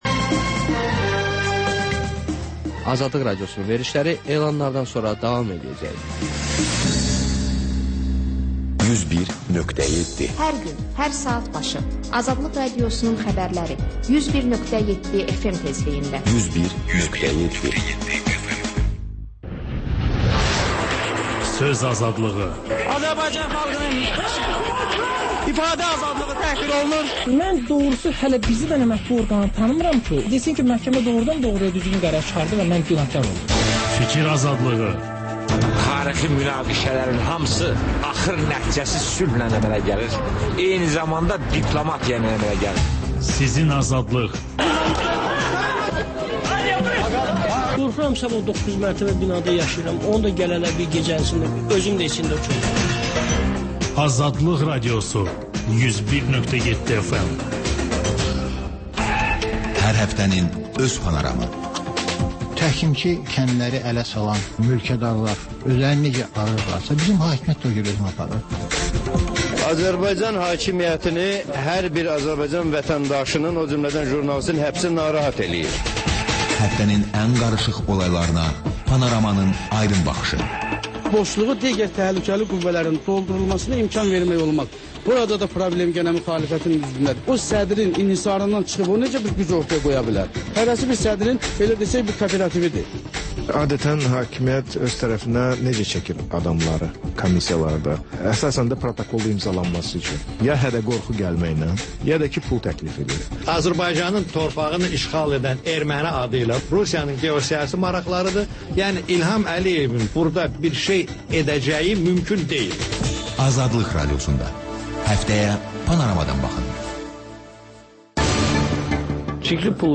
Həftə boyu efirə getmiş CAN BAKI radioşoularında ən maraqlı məqamlardan hazırlanmış xüsusi buraxılış (TƏKRAR)